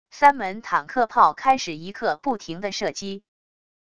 三门坦克炮开始一刻不停的射击wav音频